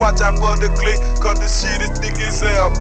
Watch Out For The Click - Dj Sound.wav